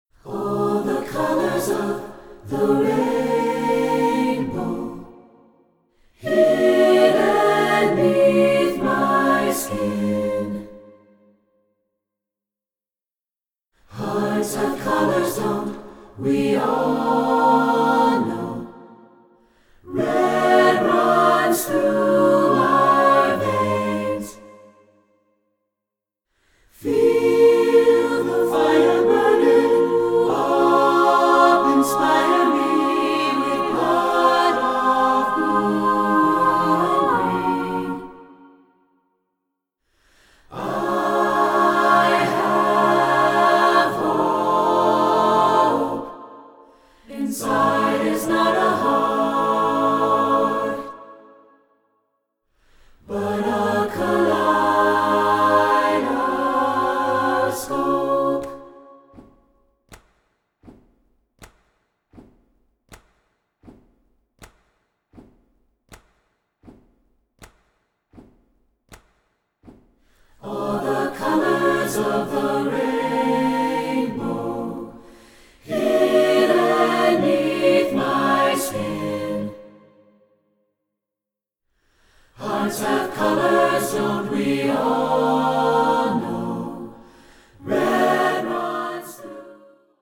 SATB with descant and body percussion.
SATB A Cap